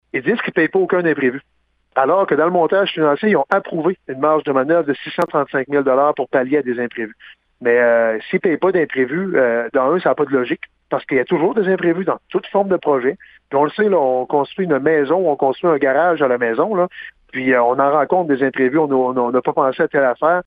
Il explique pour quelles raisons le chantier a connu des imprévus.